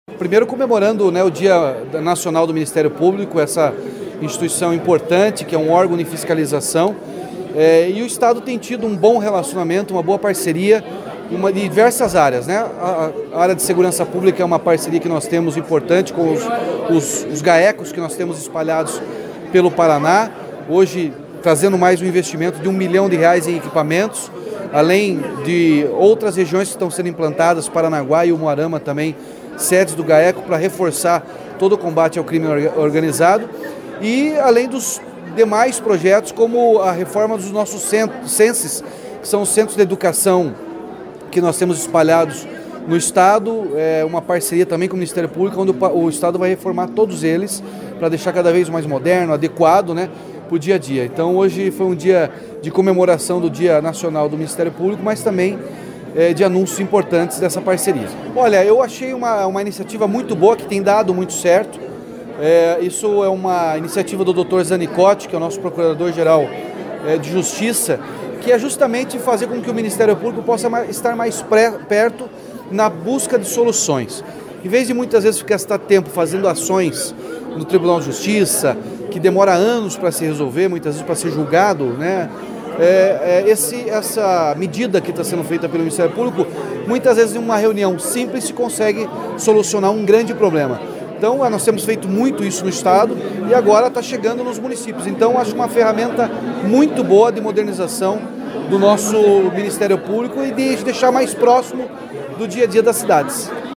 Sonora do governador Ratinho Junior sobre o investimento de R$ 168 milhões para Centros de Socioeducação